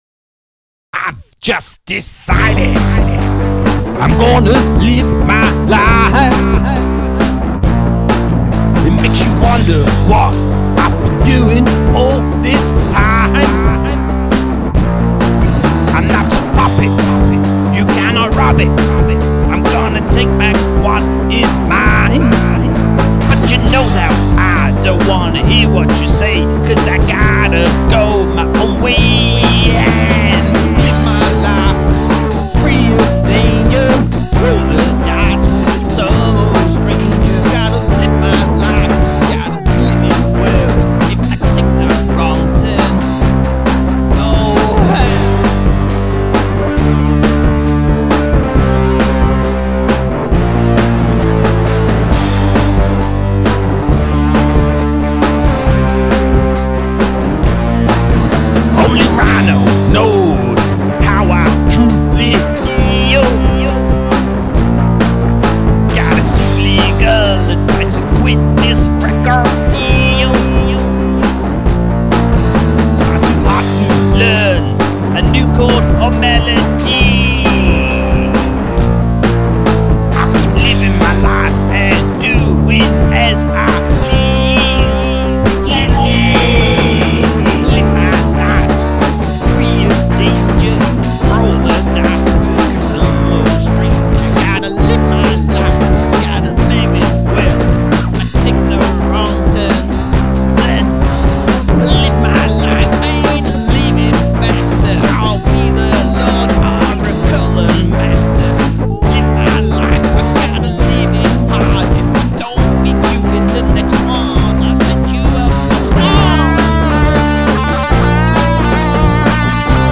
hence the ridiculously good lo-fi outro
rock anthem